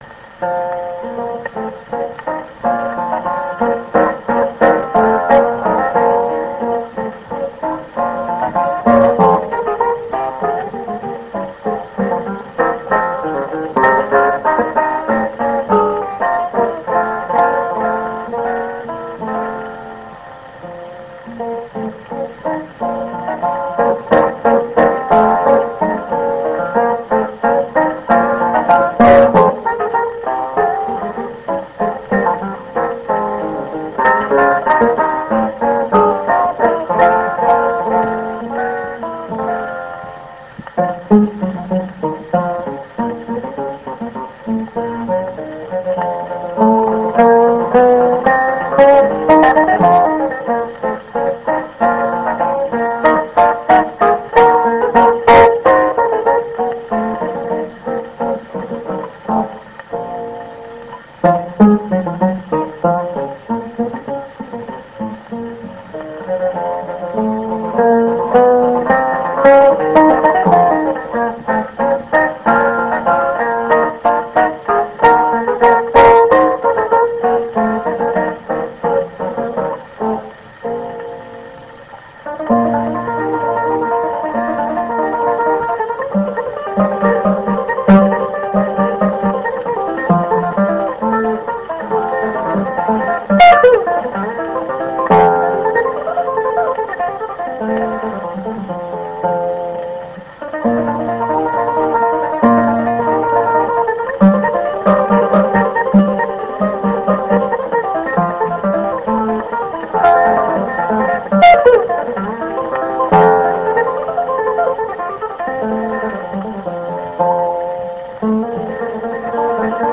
蓄音機から流れる音を録音してみました!!
ノスタルジックな雑音混じりの音楽を
ギター*ソロ
12インチSP盤